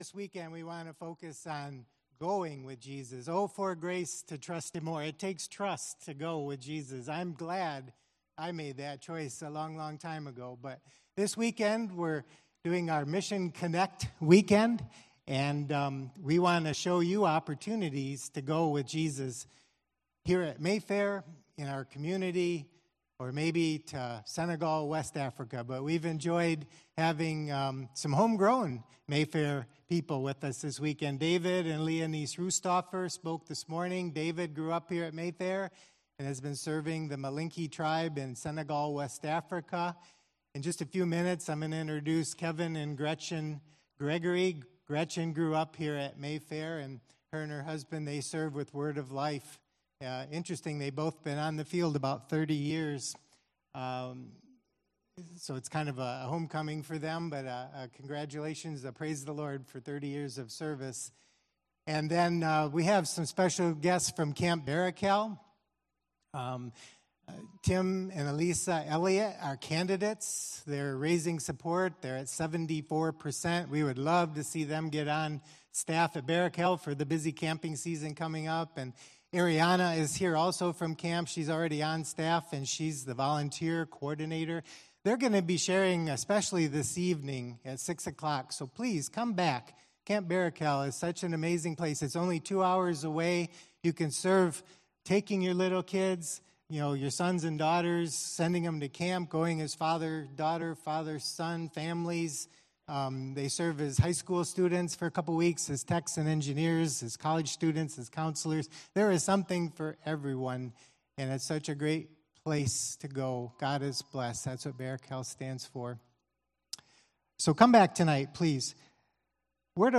Installation Service | September 15, 2024